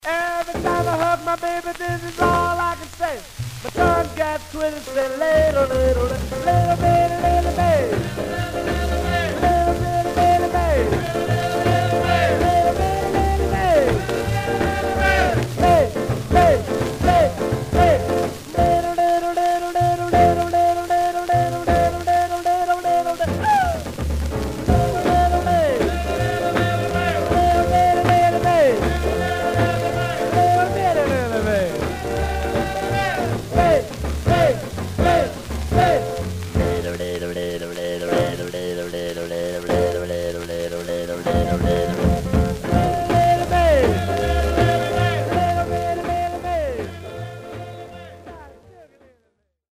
Surface noise/wear Stereo/mono Mono
Rythm and Blues